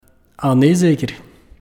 Ah nee zeker play all stop uitspraak Ah nee zeker ?